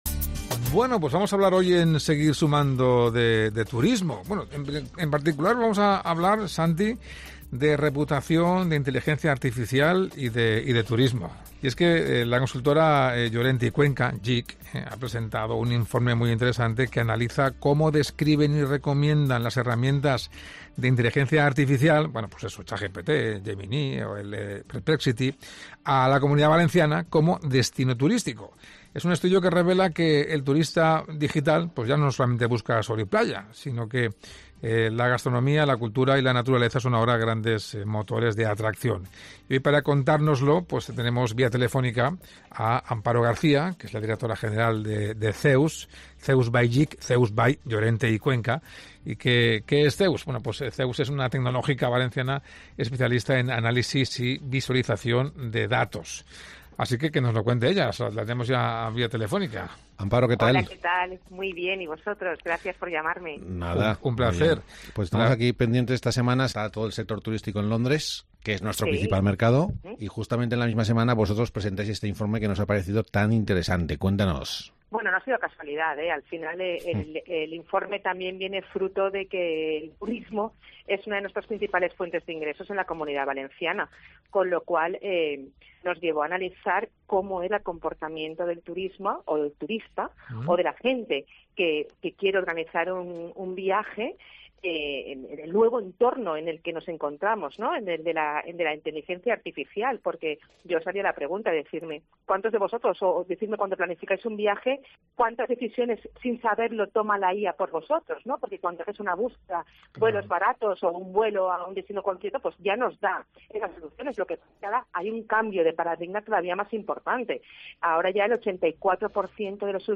ENTREVISTA DEL PROGRAMA "sEGUIR SUMANDO"